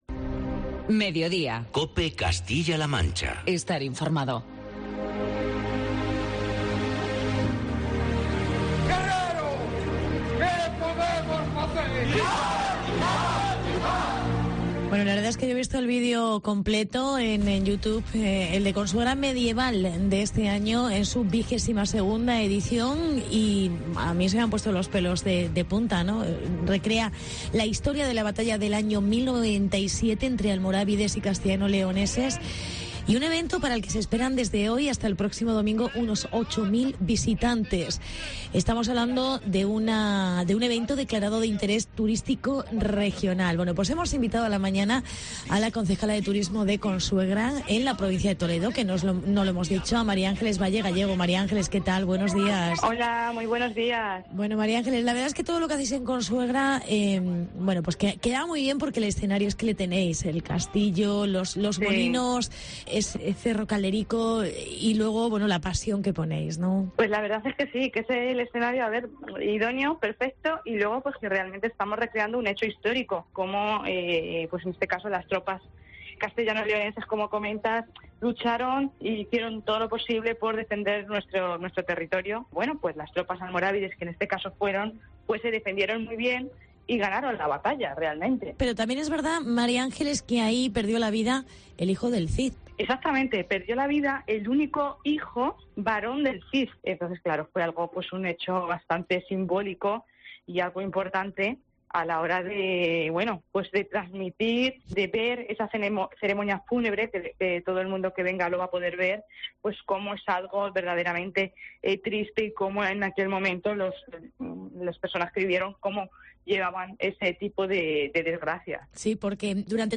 Entrevista con la concejal Mª Ángeles Valle